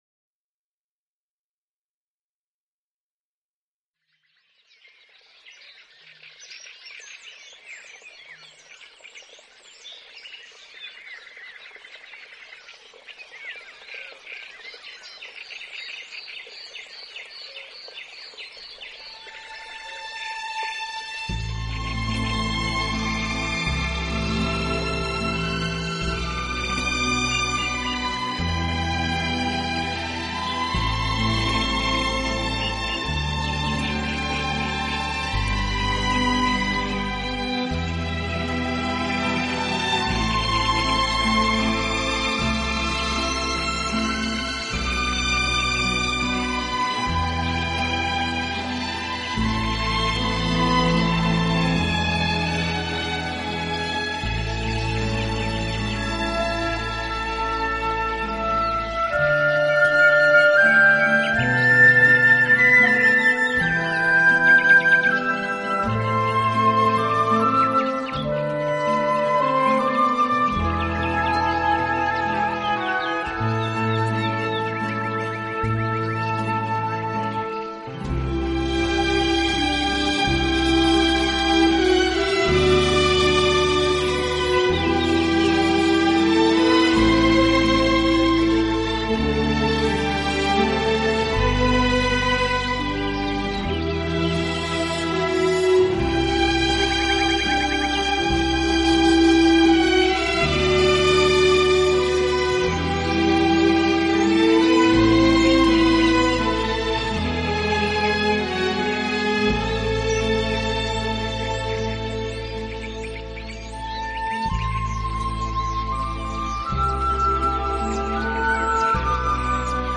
自然聲響與音樂的完美對話
新世紀的音樂形式使躁動的靈魂得到最溫柔的撫慰，你將在夜裡
海浪、流水、鳥鳴，風吹過樹葉，雨打在屋頂，
大自然的原始採樣加上改編的著名樂曲合成了天籟之音。